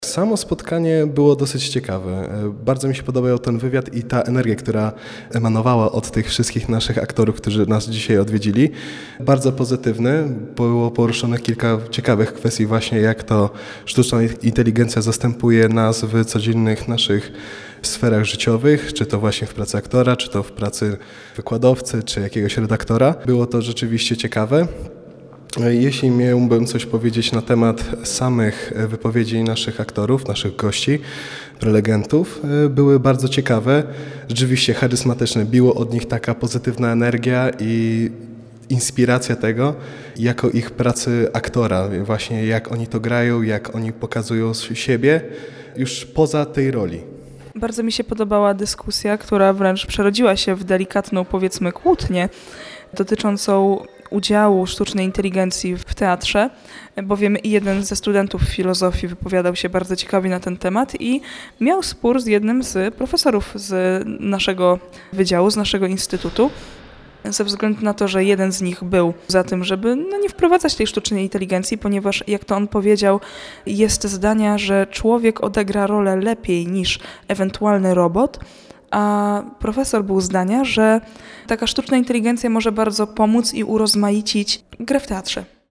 Nasza reporterka rozmawiała także ze studentami, którzy uczestniczyli we wtorkowym spotkaniu i zapytała o ich wrażenia.